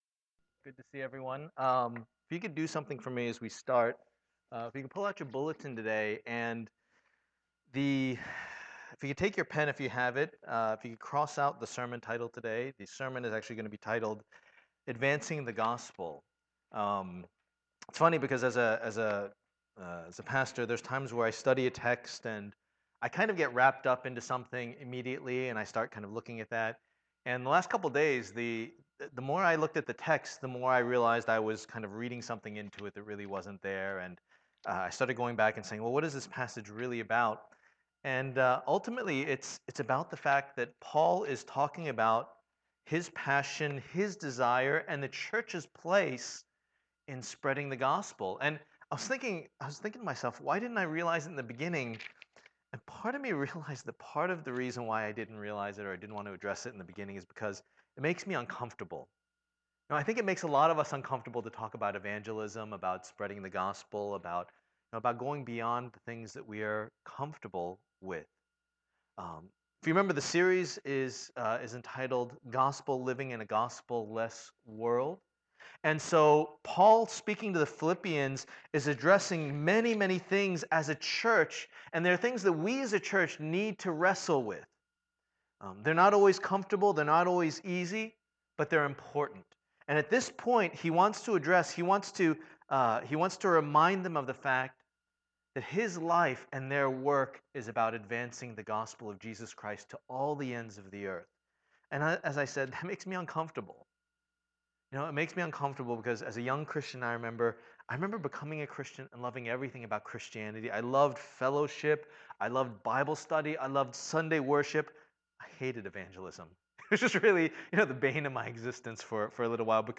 Passage: Philippians 1:12-18 Service Type: Lord's Day